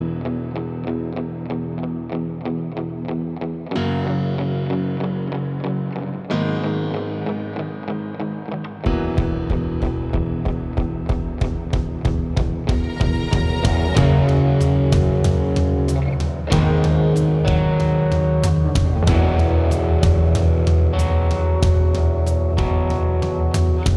No Guitars Pop (2000s) 4:56 Buy £1.50